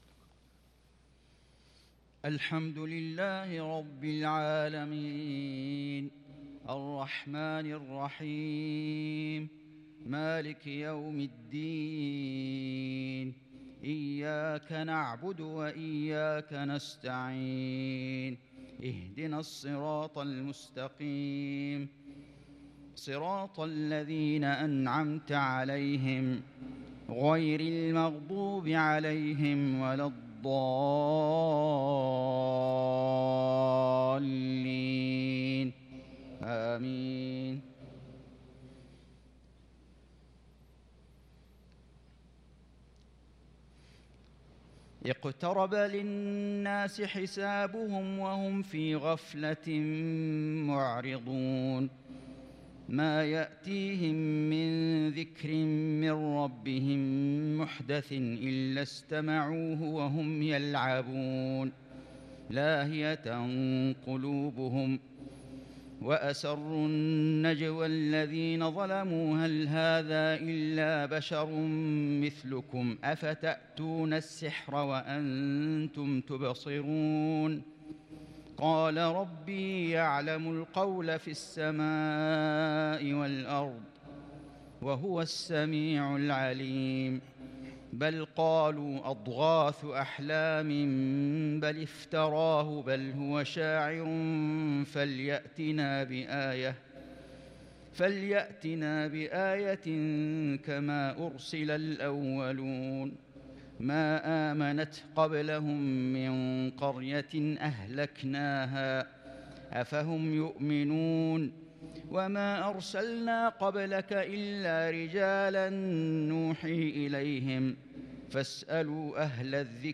صلاة العشاء للقارئ فيصل غزاوي 6 ربيع الأول 1443 هـ